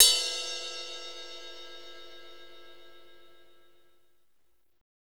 Index of /90_sSampleCDs/Northstar - Drumscapes Roland/CYM_Cymbals 2/CYM_S_S Ride x